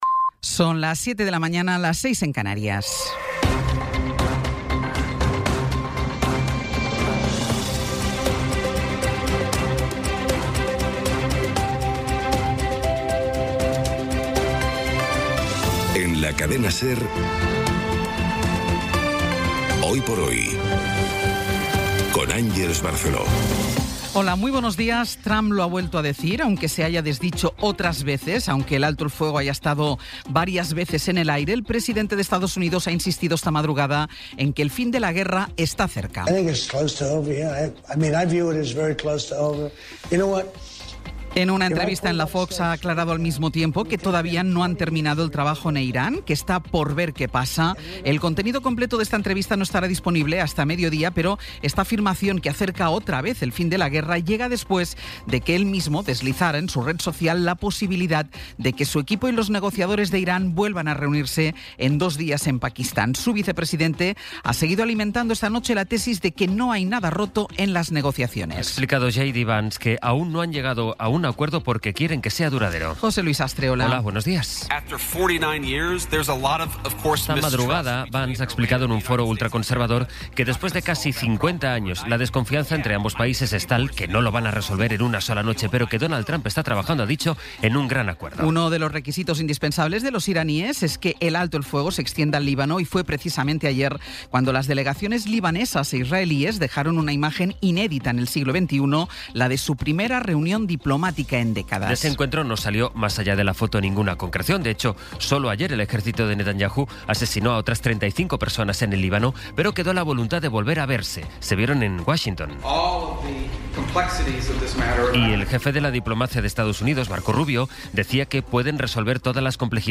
Resumen informativo con las noticias más destacadas del 15 de abril de 2026 a las siete de la mañana.